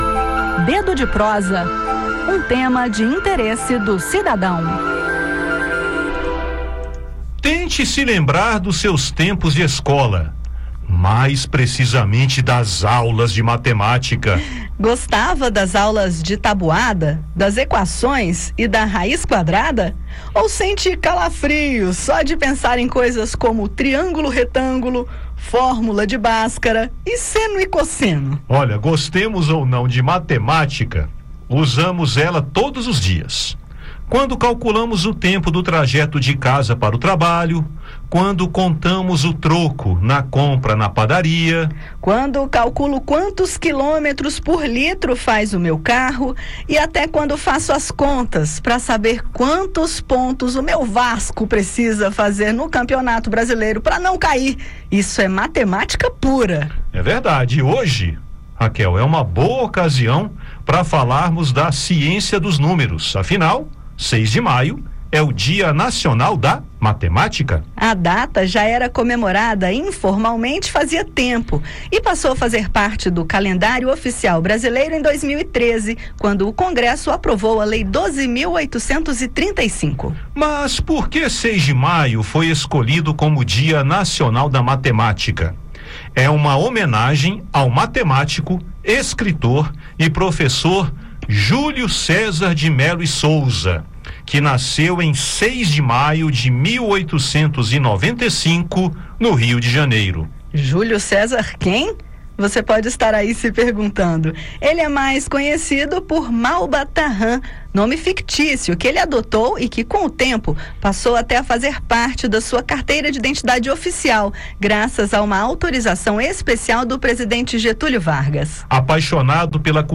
No bate-papo desta terça-feira (6), você vai saber porque o 6 de maio foi escolhido para ser o dia de homenagear a matemática e os profissionais da área.